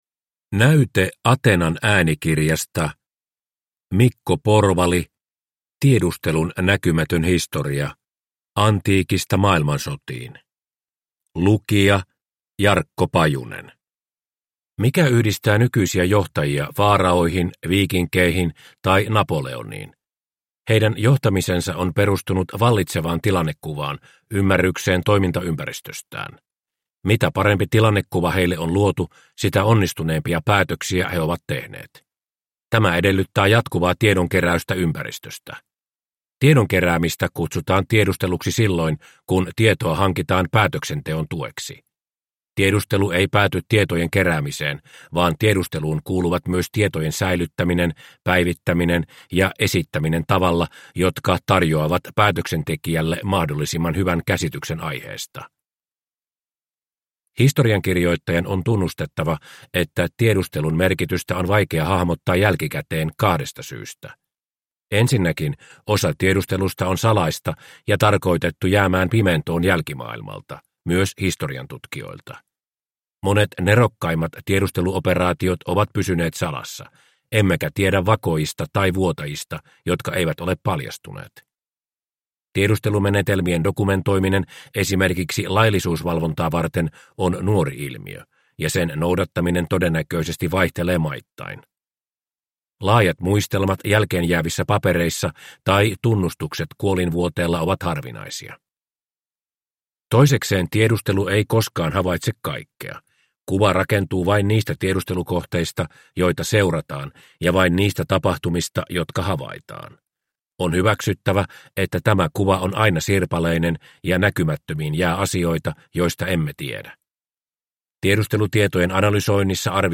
Tiedustelun näkymätön historia – Ljudbok – Laddas ner